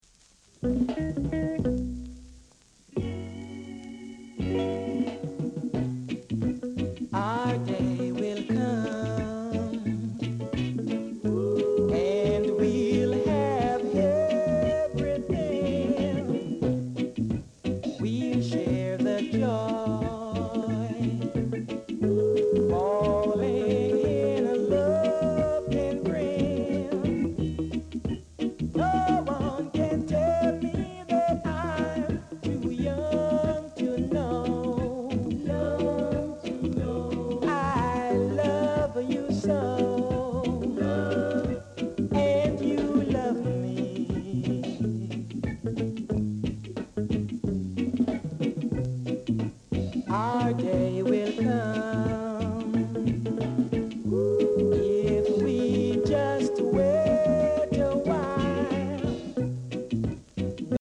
Reggae Male Vocal, Vocal Group